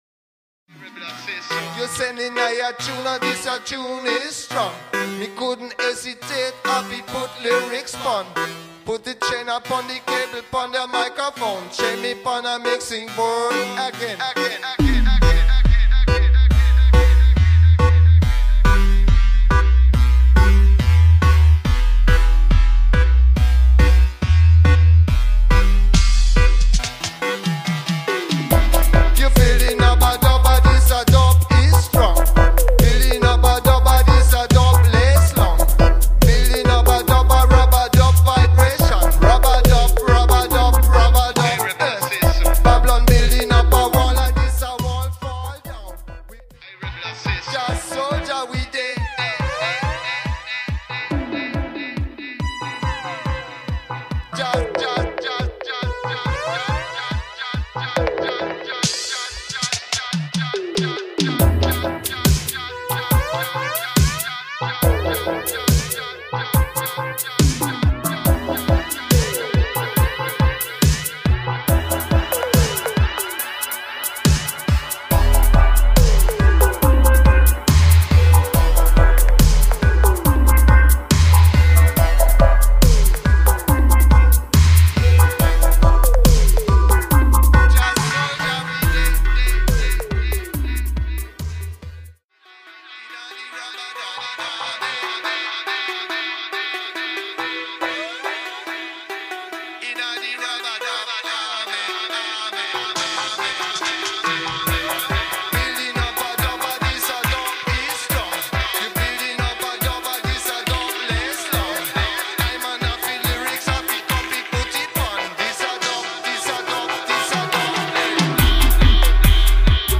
good reggae music